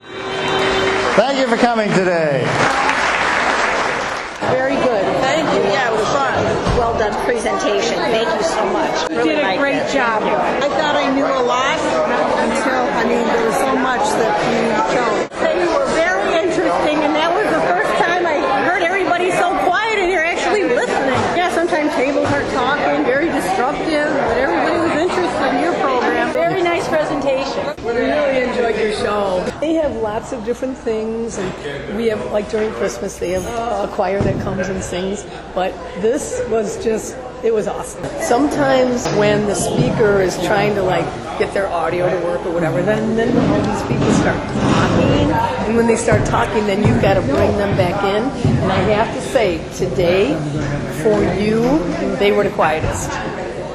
Seniors learn new things about Lucy:
Lucy-Audience-Comments.mp3